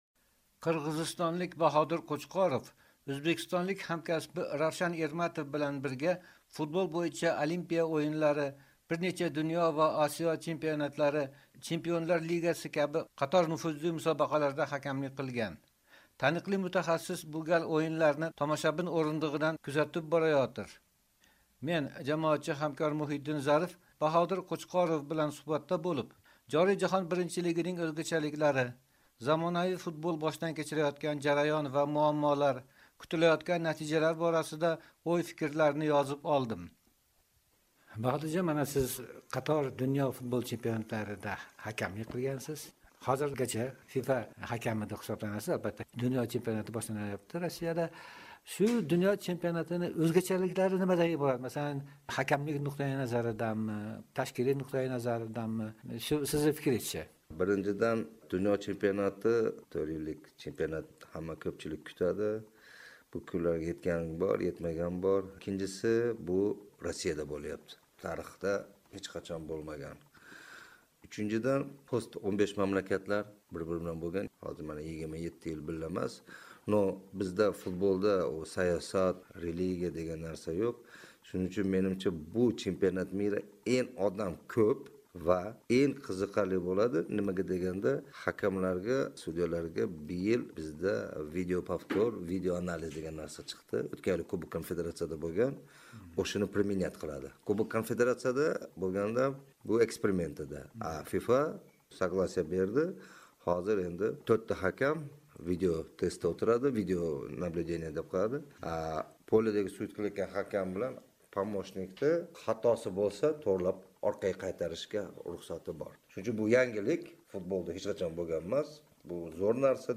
suhbat